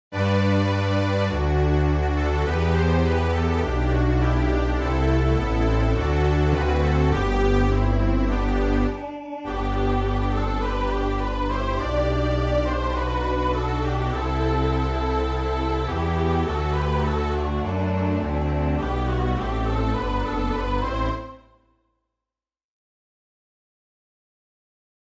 This arrangement includes a unison voice part.